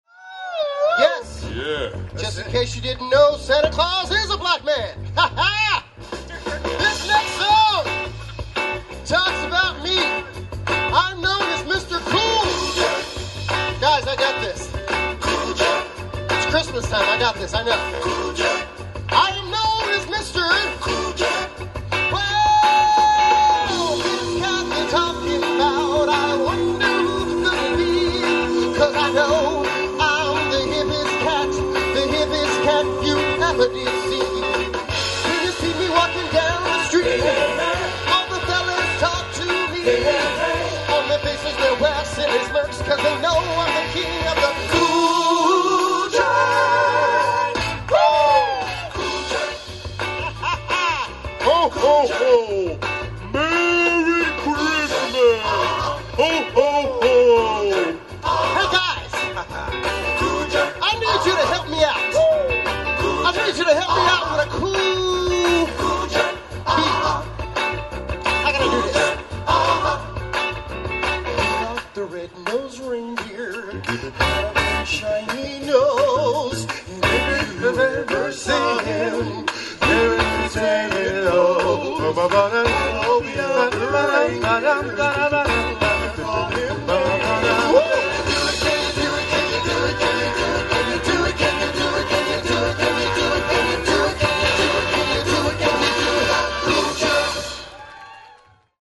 今日の１回目のショー,ビデオ撮ってなくて,このレアなクリスマスソングをもう１度聴きたかったんです♪”